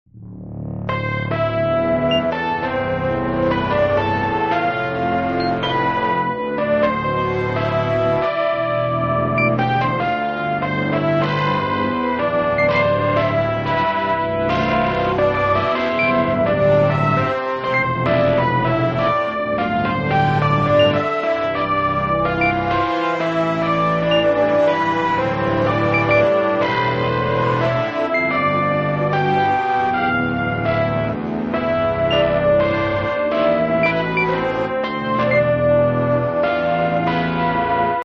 experimental electronic, ambient,